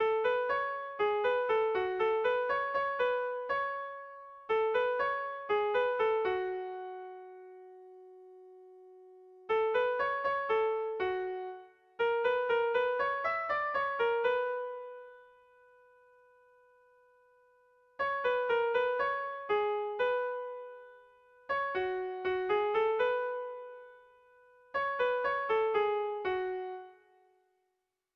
Irrizkoa
Zortzikoa, txikiaren moldekoa, 4 puntuz (hg) / Lau puntukoa, txikiaren modekoa (ip)
A1A2BD